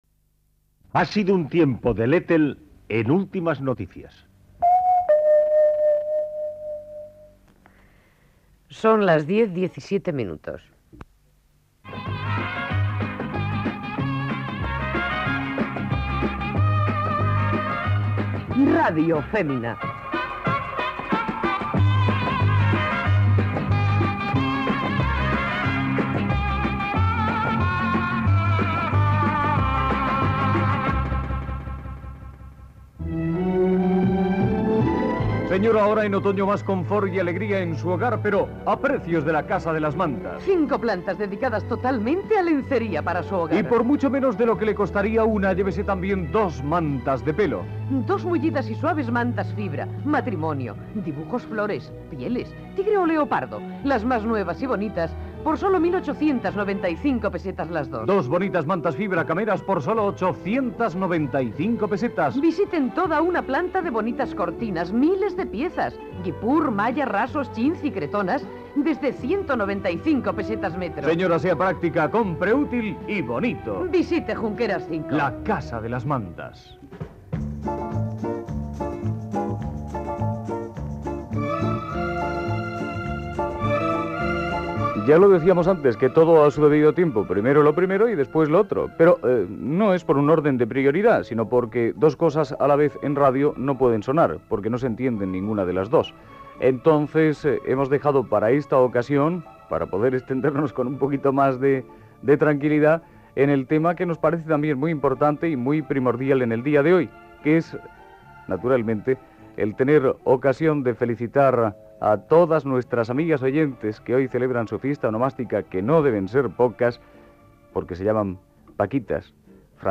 Hora, identificació, publicitat, felicitacions a les Paquitas i Pacos, disc, publicitat.
Info-entreteniment